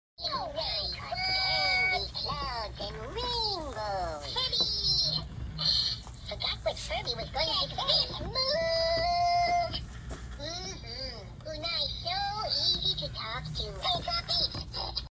25 Years Of Furby In Sound Effects Free Download